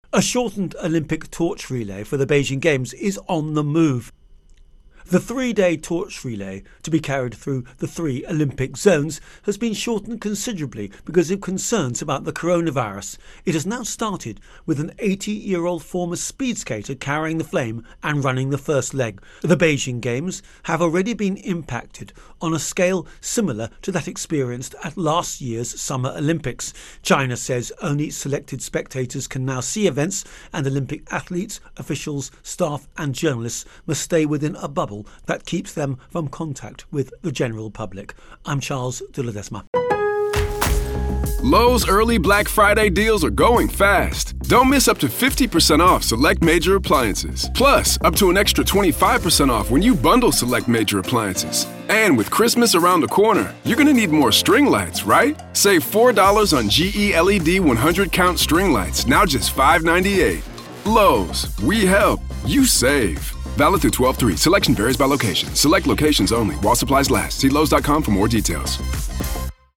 Olympics-Torch Relay Intro and Voicer